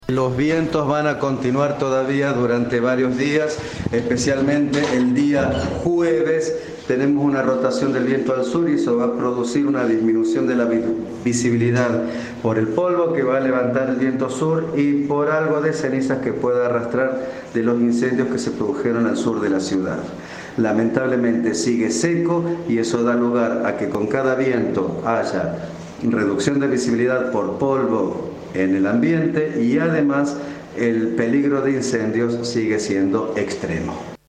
Informe de Siempre Juntos.